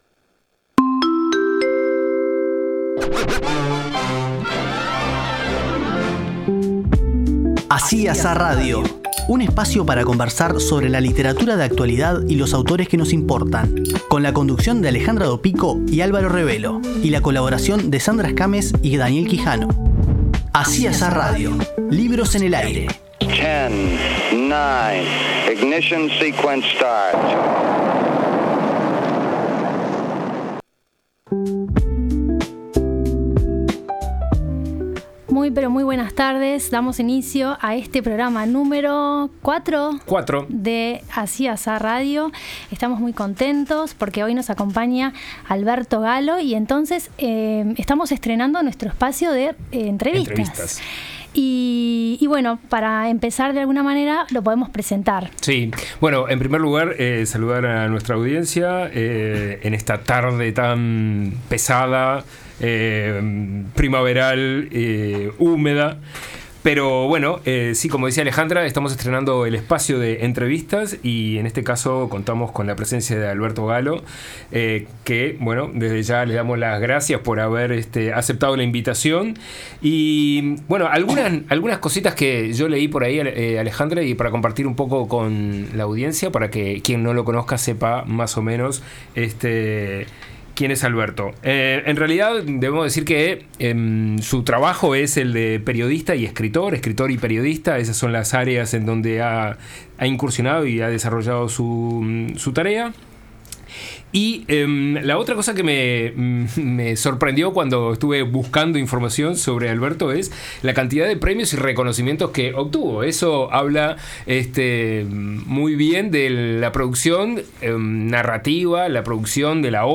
Así y Asá. Programa 4. Entrevista